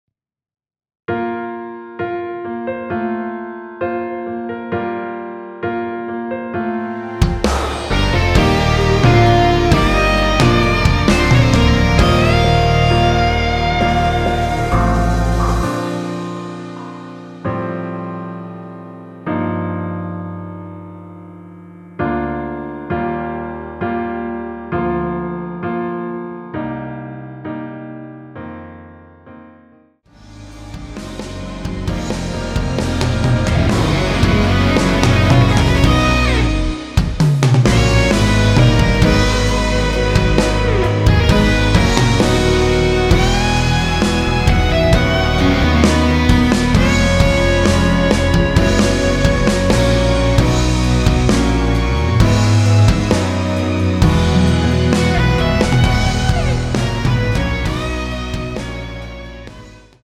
원키에서(-3)내린 MR입니다.
Bb
앞부분30초, 뒷부분30초씩 편집해서 올려 드리고 있습니다.
중간에 음이 끈어지고 다시 나오는 이유는